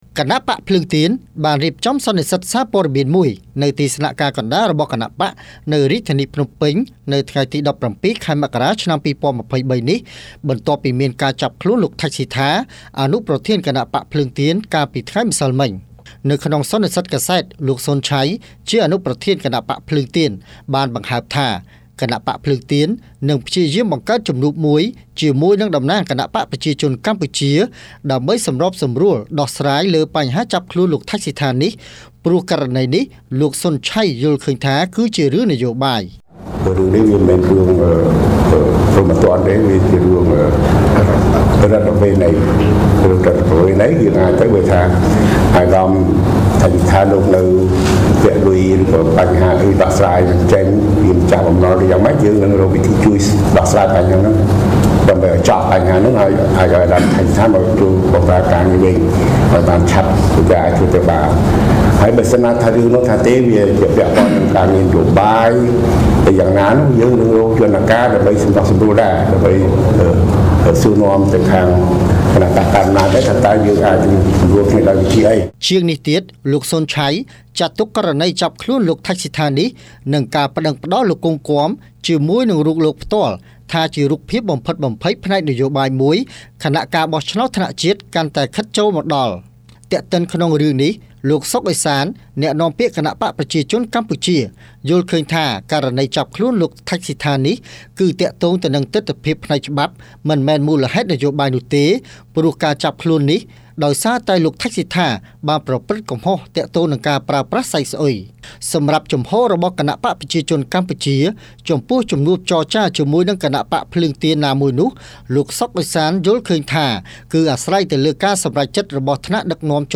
Radio
រាយការណ៍ពីព័ត៌មាននេះ